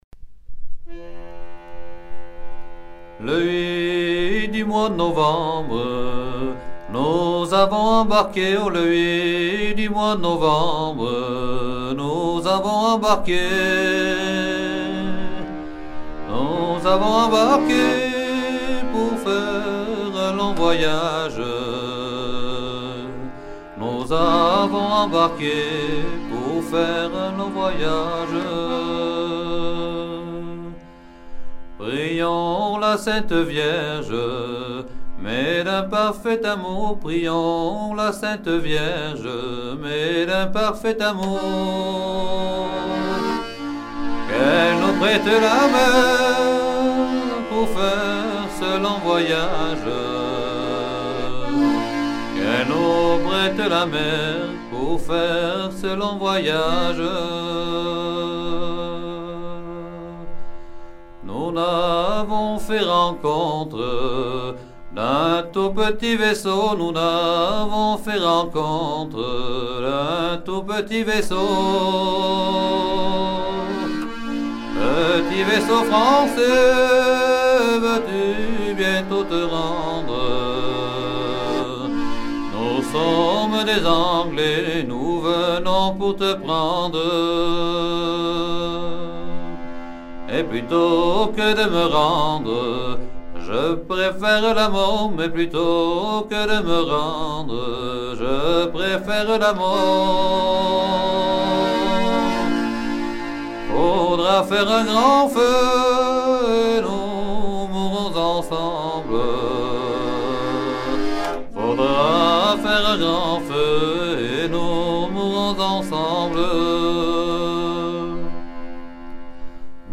Version recueillie vers 1980
Genre strophique
Chants de mariniers